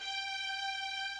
Shady Aftermath Orchestral Stringz.wav